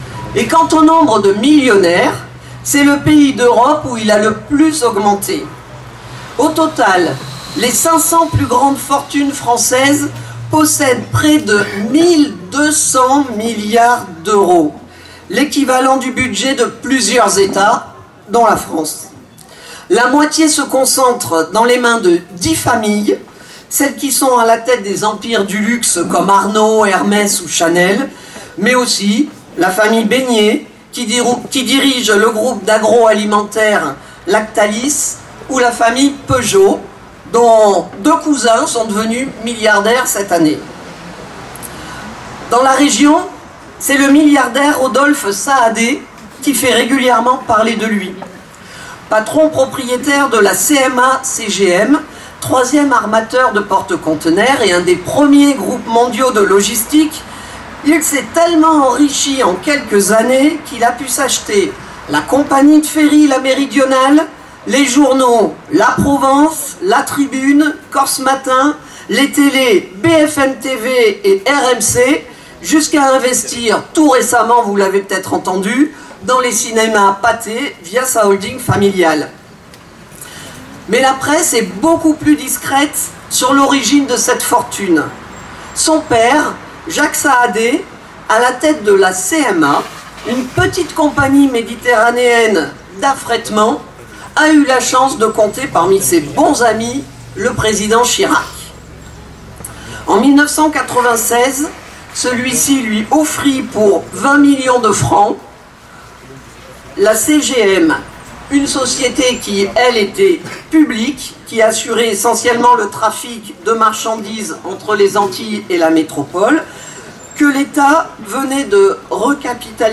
Fête LO 2025 à Marseille